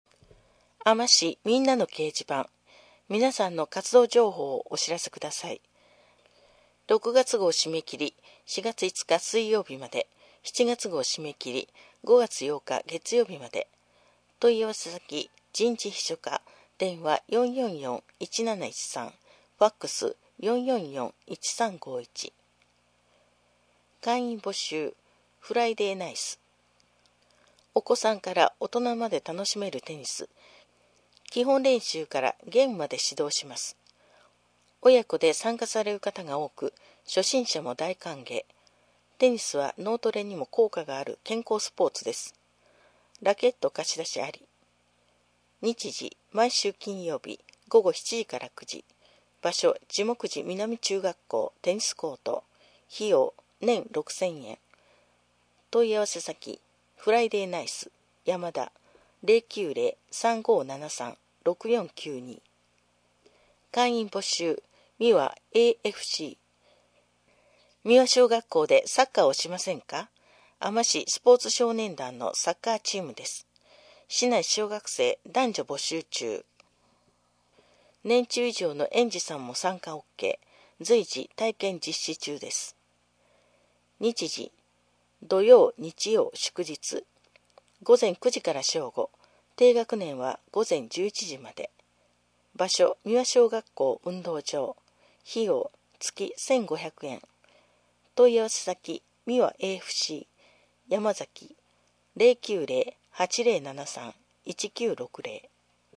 声の広報4月